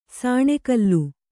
♪ sāṇe kallu